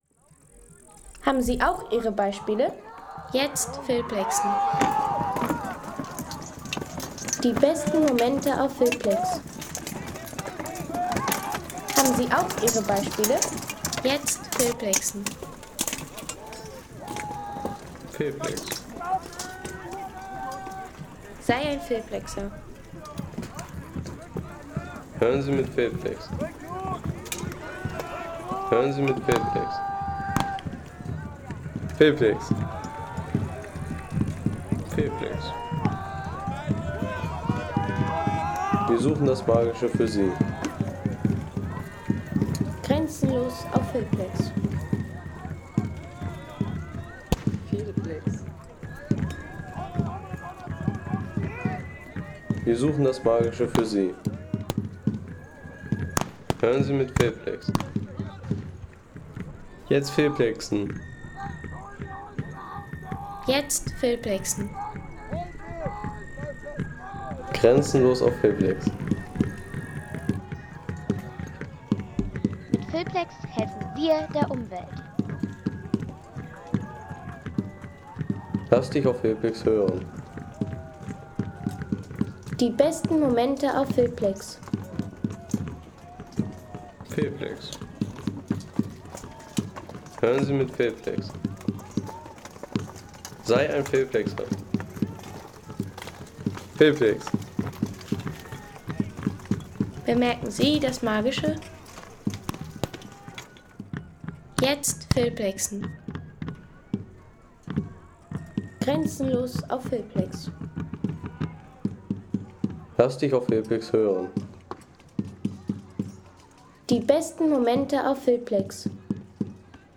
Wilde Klänge der Schlacht
Historische Klänge einer inszenierten mittelalterlichen Schlacht.